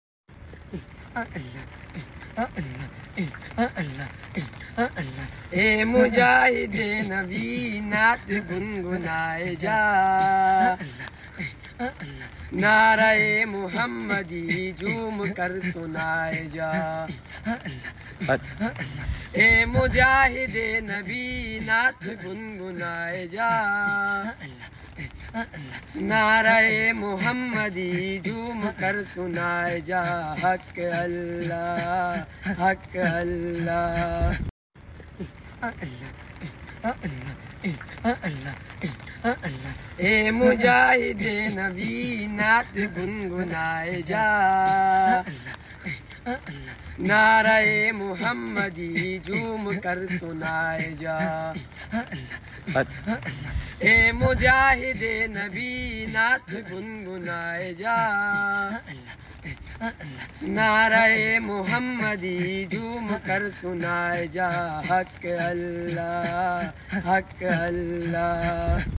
Alhumdulillah naat sharif